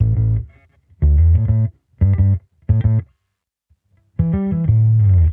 Index of /musicradar/sampled-funk-soul-samples/90bpm/Bass
SSF_PBassProc2_90A.wav